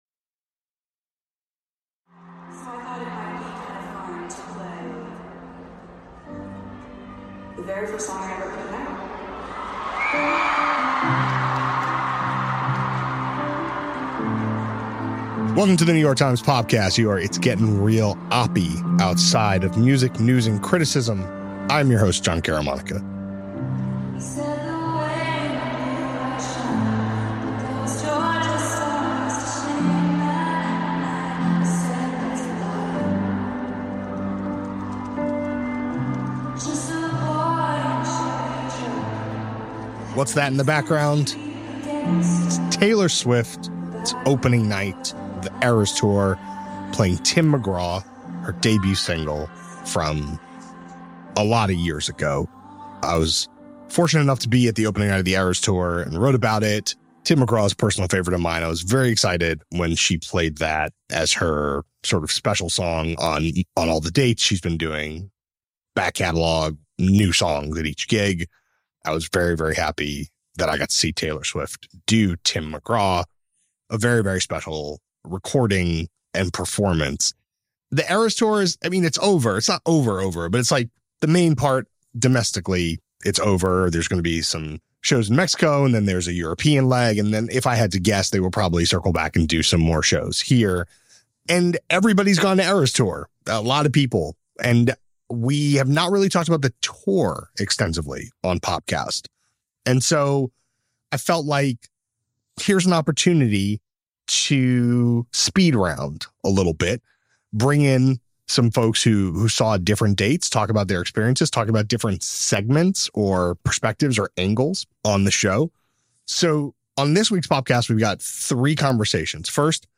A conversation about how the concert relates to Beyoncé’s stadium show, the role of dance in Swift’s spectacular and more.